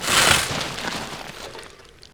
wetshovel.wav